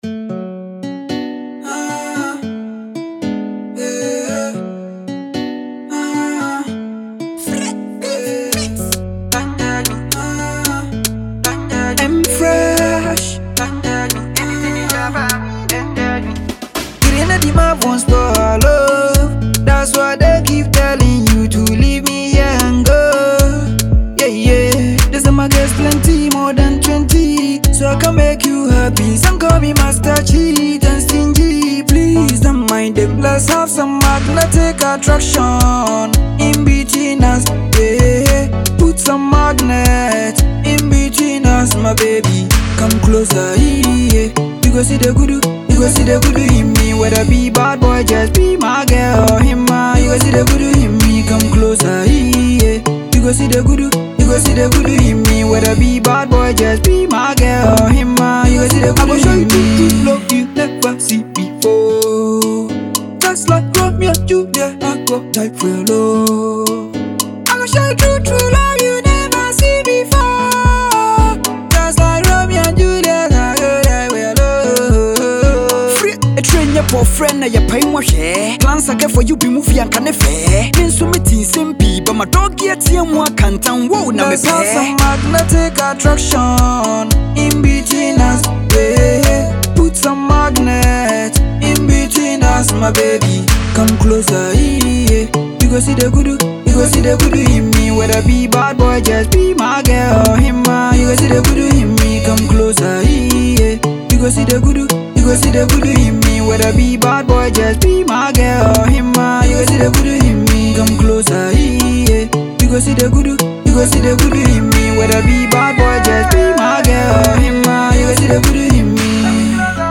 With his melodic vocals and heartfelt lyrics